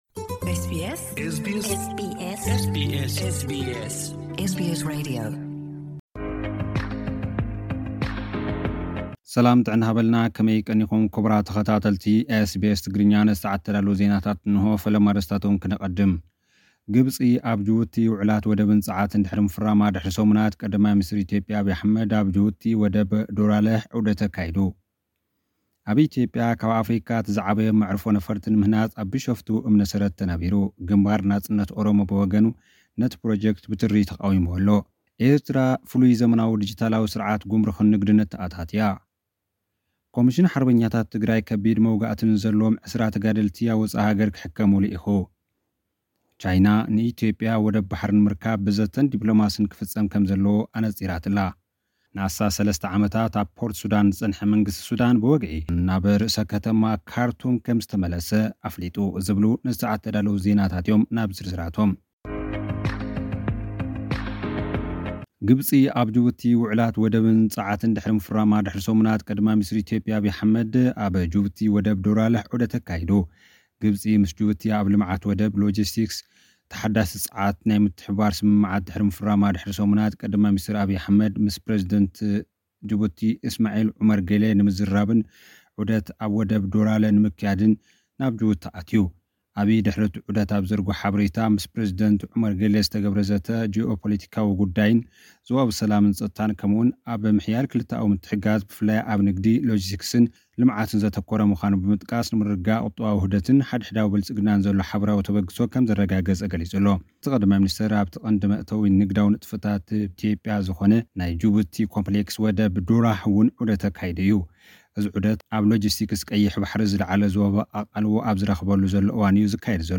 ጸብጻባት ልኡኽና